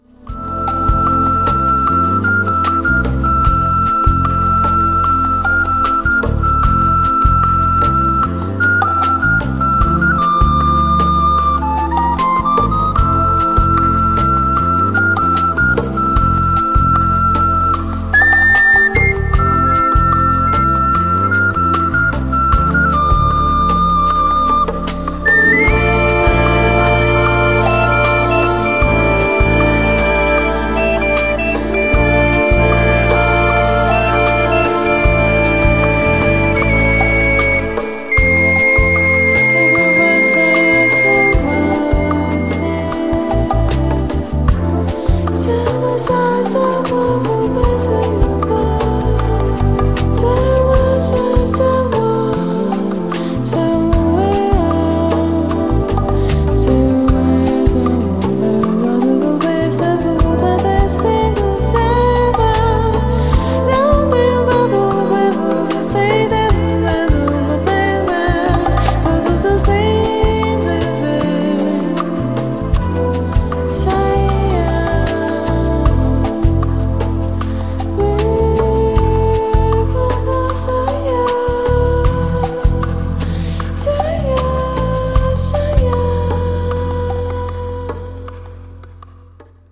CD v súčasnom štýle groove-fusion-smooth-nu-...-jazz.
flauta, píšťalka, spev